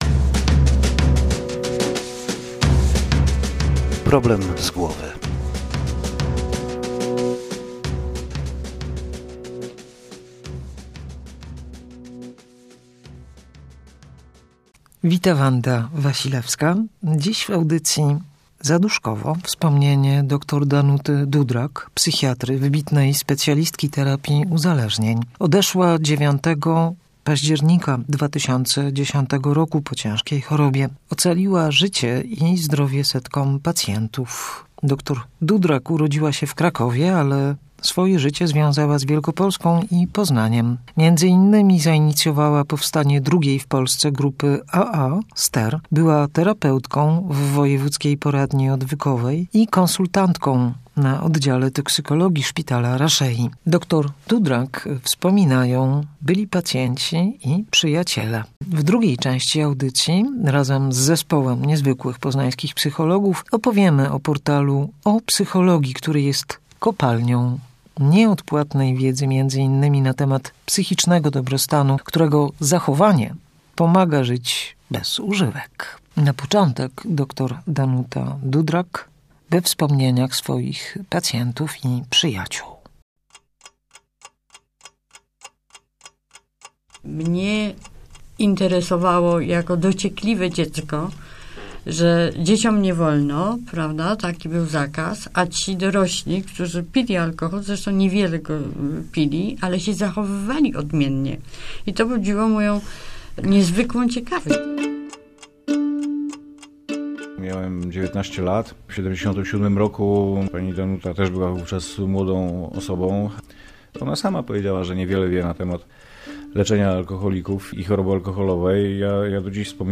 Wspominać będą byli pacjenci i przyjaciele.